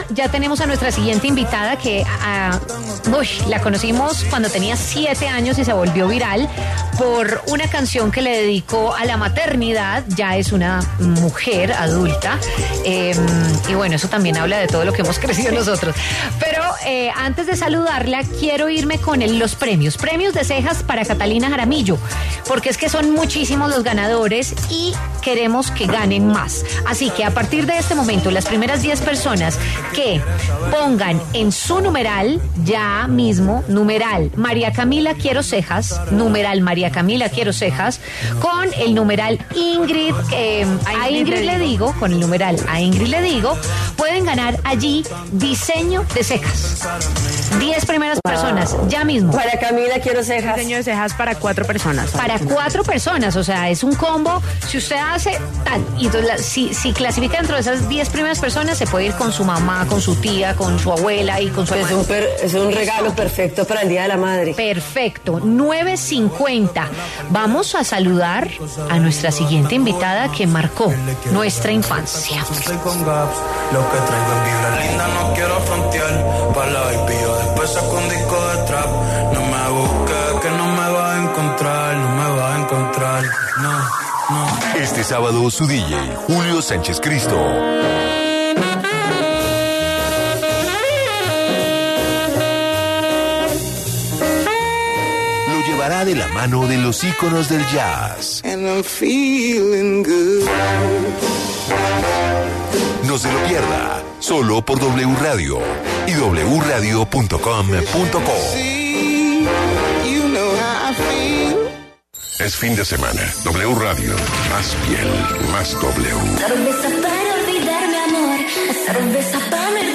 En diálogo con W Fin de Semana, la cantante peruana Wendy Sulca conversó sobre su evolución musical y su más reciente trabajo discográfico titulado ‘Evolución’.